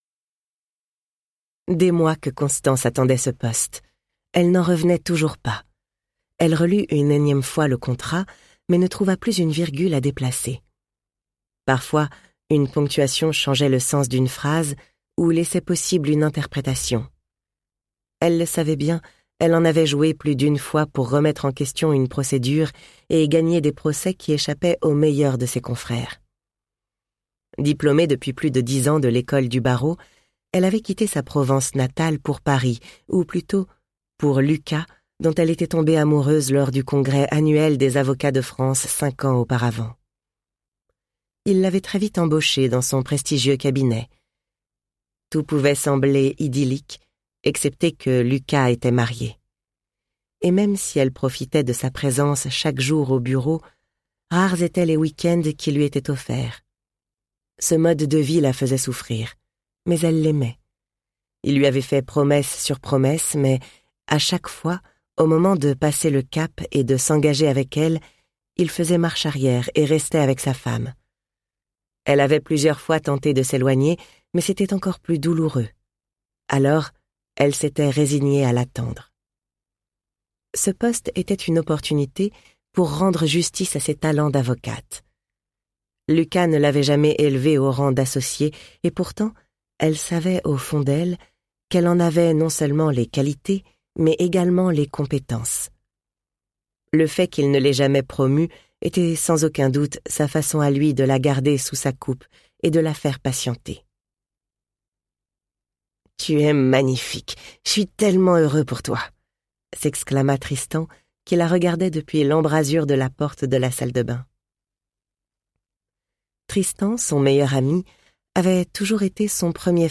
Extrait gratuit - Plus jamais sans moi de Maud Ankaoua